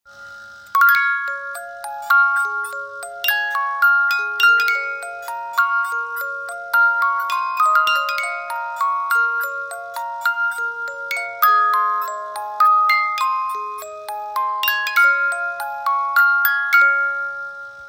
Romántico